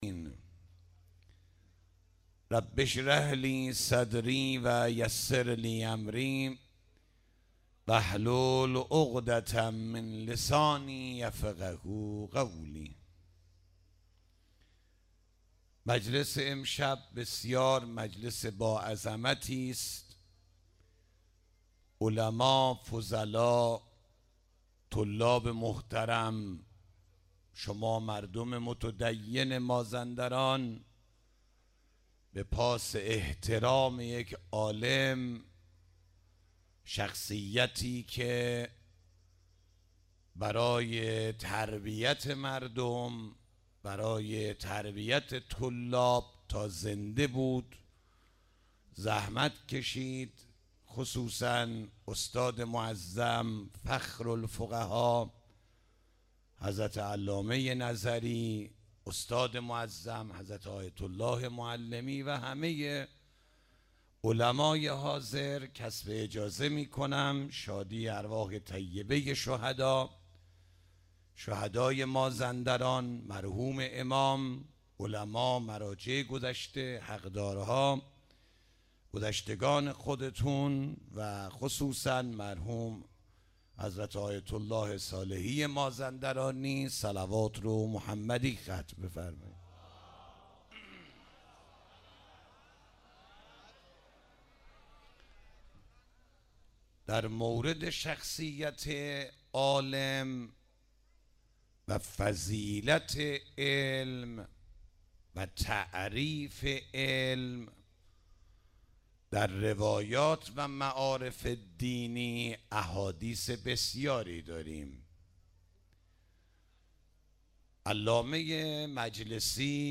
31 تیر 96 - سالگردمرحوم‌ آیت الله صالحی مازندرانی - سخنرانی